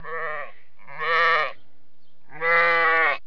جلوه های صوتی
دانلود صدای حیوانات جنگلی 112 از ساعد نیوز با لینک مستقیم و کیفیت بالا